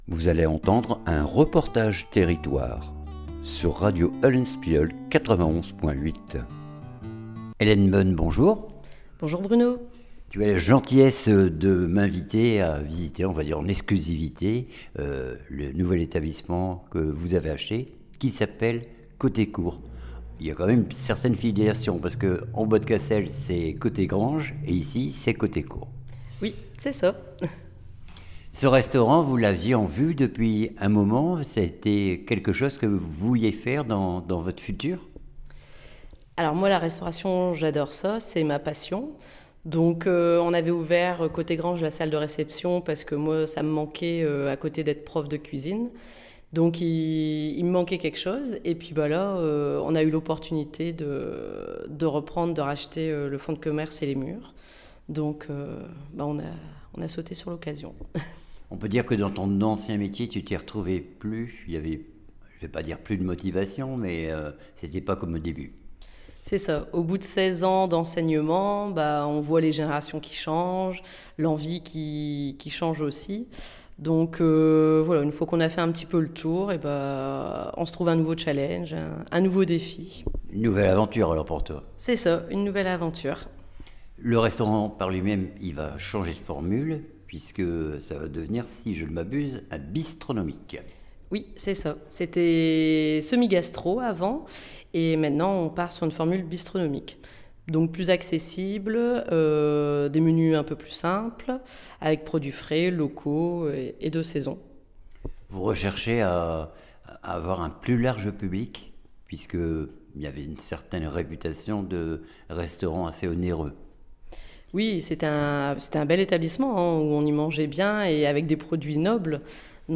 REPORTAGE TERRITOIRE COTE COUR - COTE GITES CASSEL